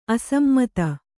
♪ asammata